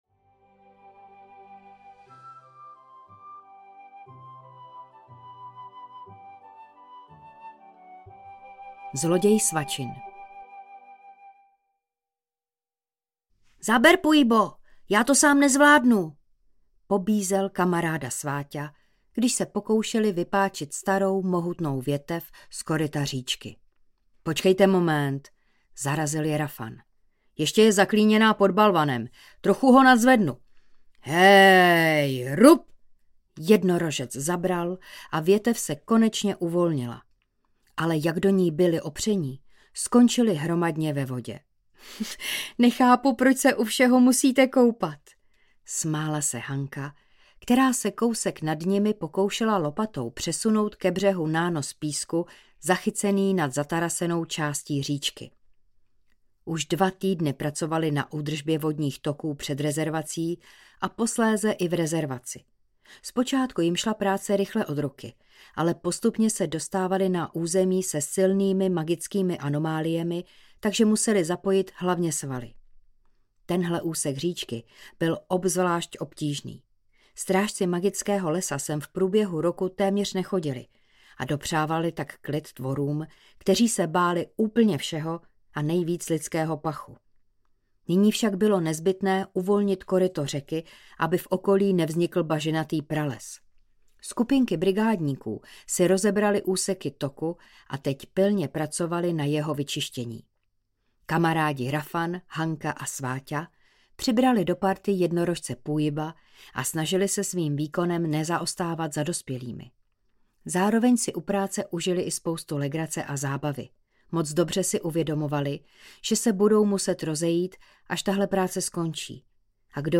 Únosce draků audiokniha
Ukázka z knihy